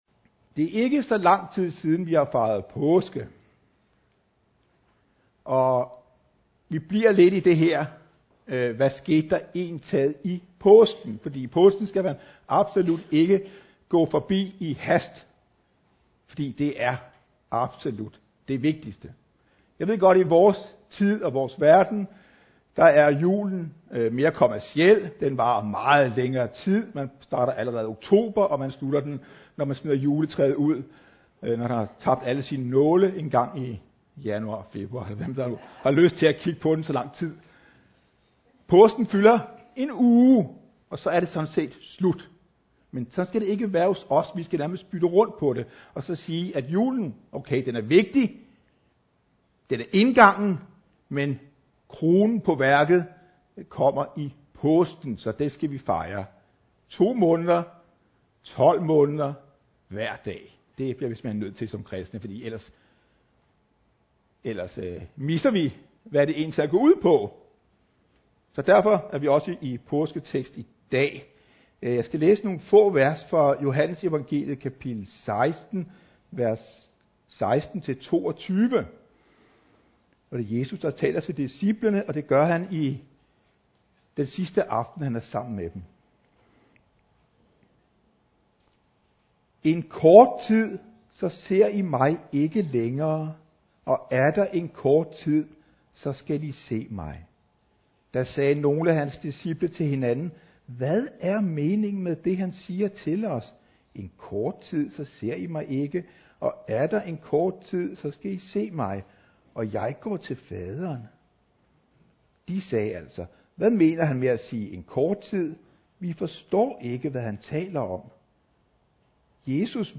7. maj 2017 Type af tale Prædiken Bibeltekst Johannes Evangeliet MP3 Hent til egen PC